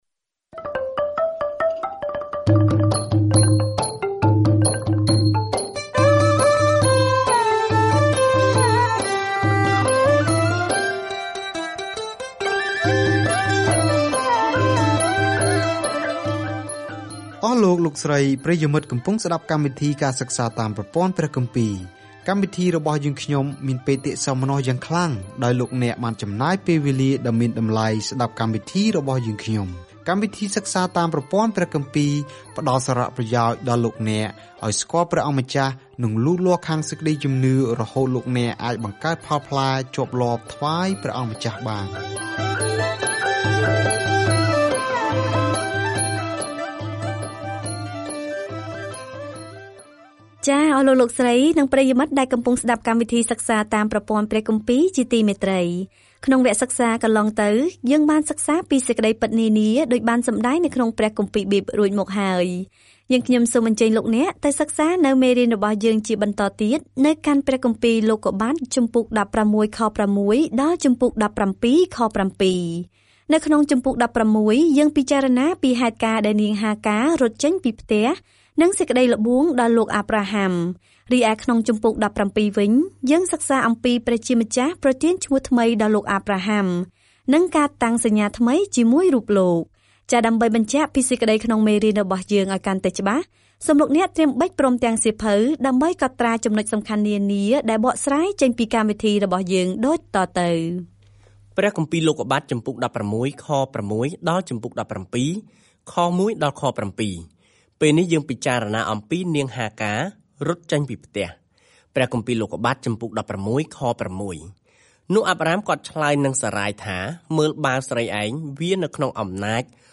ការធ្វើដំណើរប្រចាំថ្ងៃតាមរយៈលោកុប្បត្តិ នៅពេលអ្នកស្តាប់ការសិក្សាជាសំឡេង ហើយអានខគម្ពីរដែលបានជ្រើសរើសពីព្រះបន្ទូលរបស់ព្រះនៅក្នុងសៀវភៅលោកុប្បត្តិ។